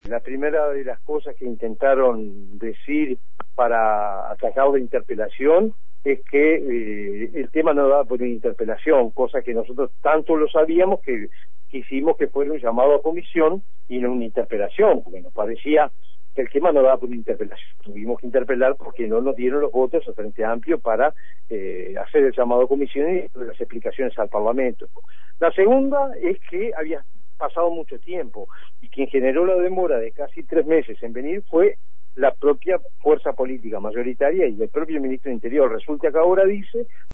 El miembro convocante, Pablo Iturralde, dijo a El Espectador que Bonomi le tomó "el pelo al Parlamento";.